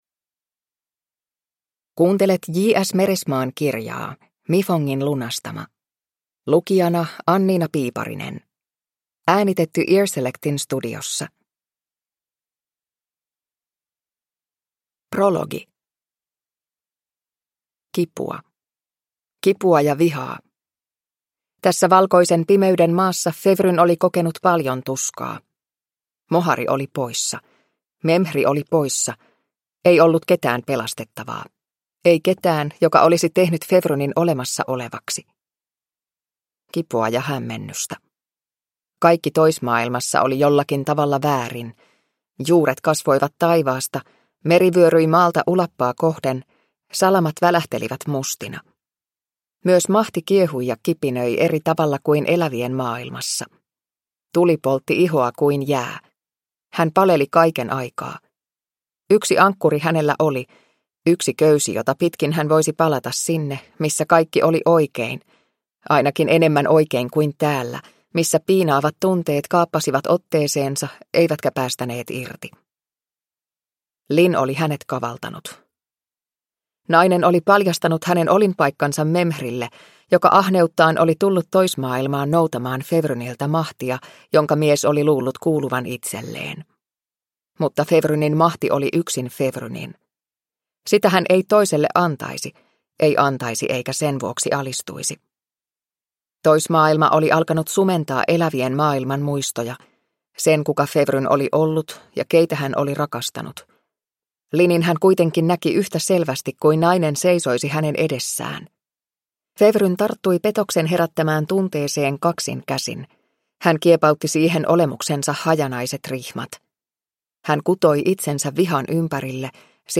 Mifongin lunastama (ljudbok) av J.S. Meresemaa